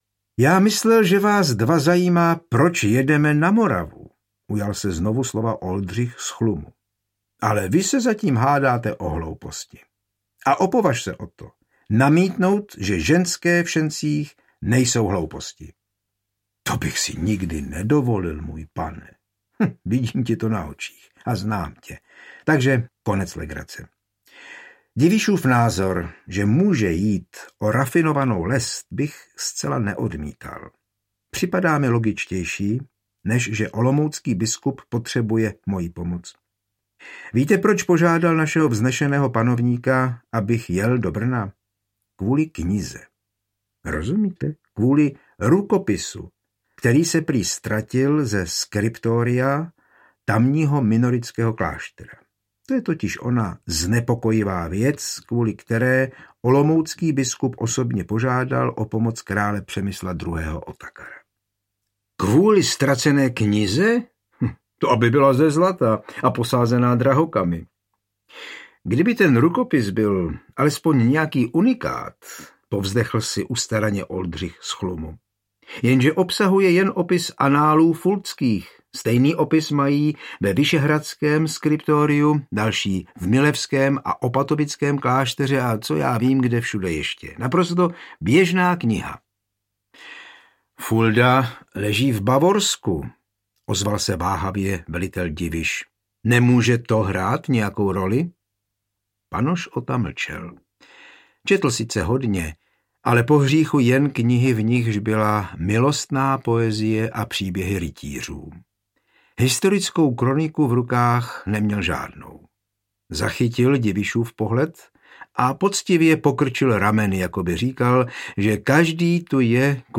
Oldřich z Chlumu - Zločiny v Markrabství moravském audiokniha
Bez hudebních předělů a podkresů.
Ukázka z knihy